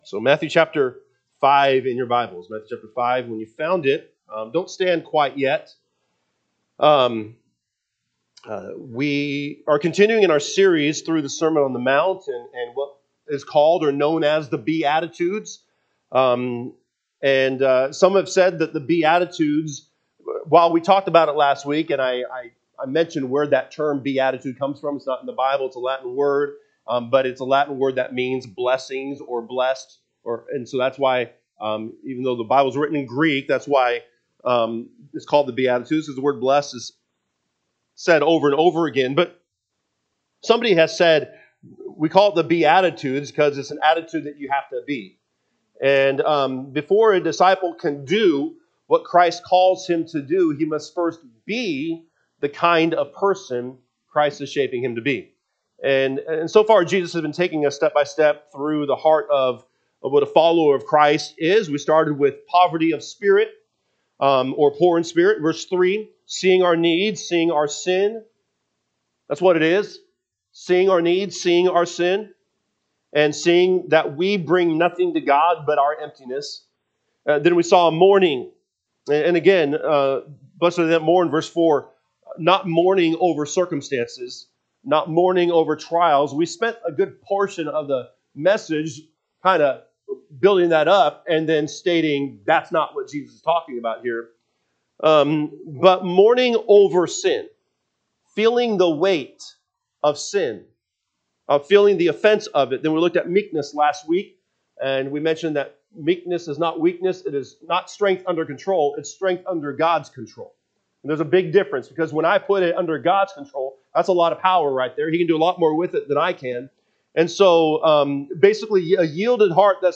February 15, 2026 am Service Matthew 5:1-6 (KJB) 5 And seeing the multitudes, he went up into a mountain: and when he was set, his disciples came unto him: 2 And he opened his mouth, and …